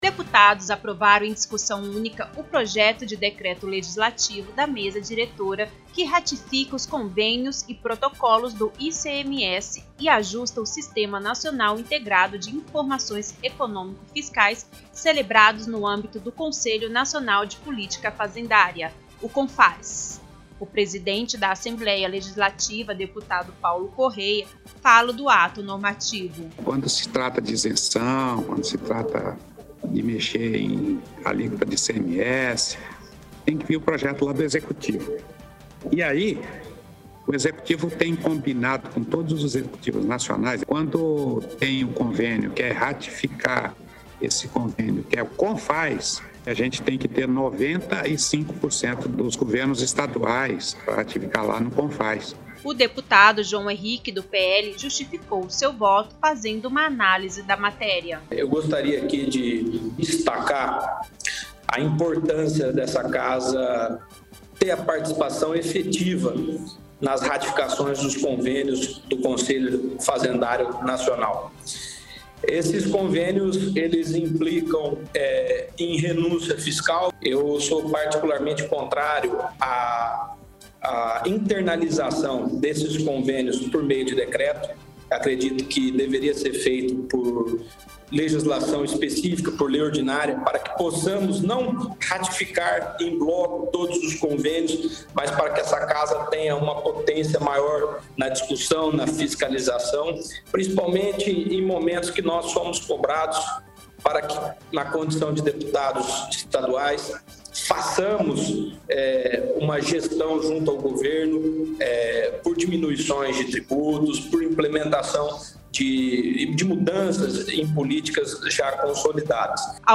Locução e Produção: